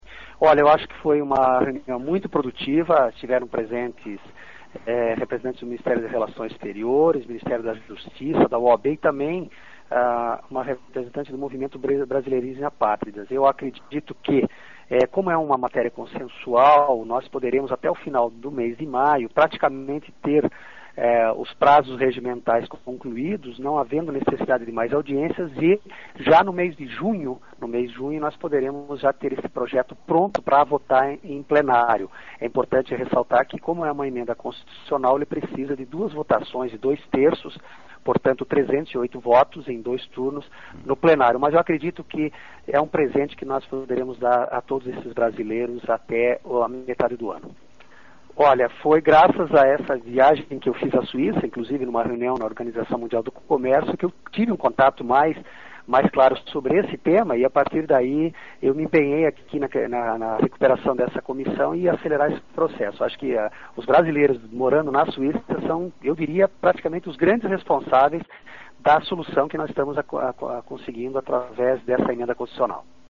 Deputado Federal Carlito Merss (PT-SC), presidente da Comissão Especial que analisa a PEC dos brasileirinhos, fala da reunião de 10 de maio em Brasília e do papel desempenhado pelos brasileiros da Suíça.